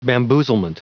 Prononciation du mot : bamboozlement
bamboozlement.wav